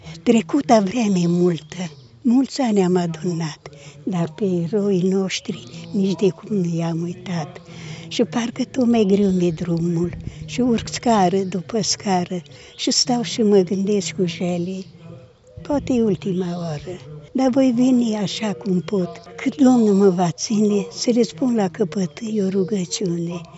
Ceremonia militară și religioasă de comemorare a eroilor, cu depuneri de coroane și jerbe de flori, a avut loc la orele prânzului, la Cimitirul Eroilor din Oarba de Mureş.